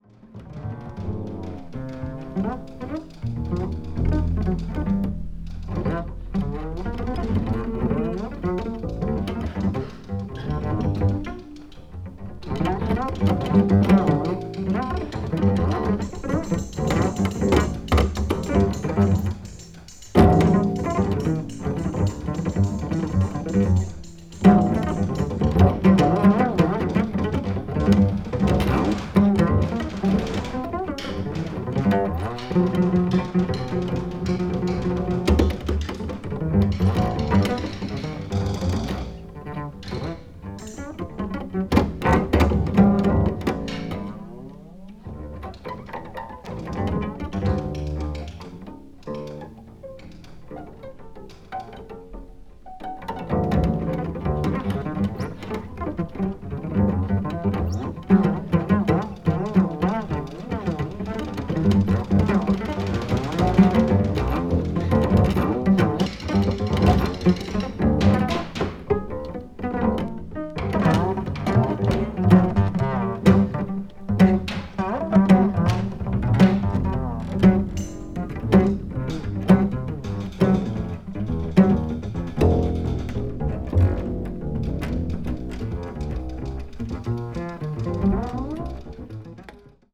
media : EX/EX(わずかにチリノイズが入る箇所あり)
avant-jazz   contemporary jazz   deep jazz   free jazz